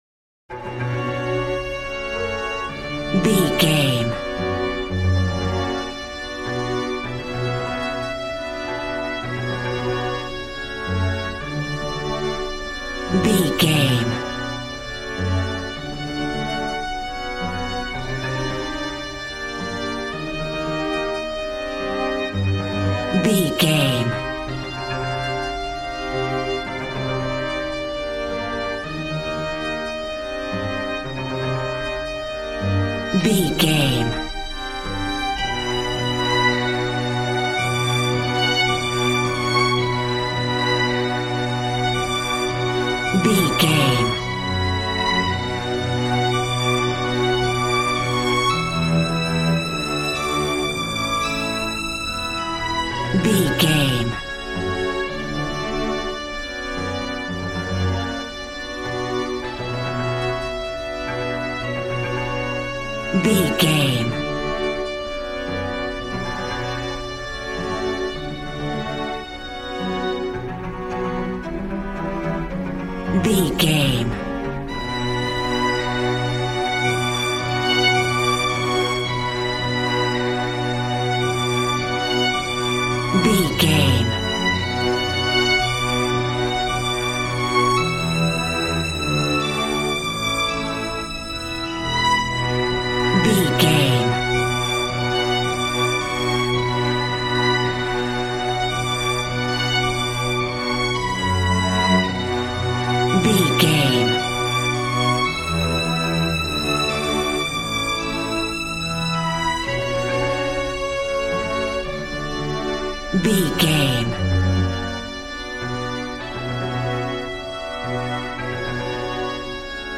Beautiful stunning solo string arrangements.
Regal and romantic, a classy piece of classical music.
Aeolian/Minor
regal
strings
brass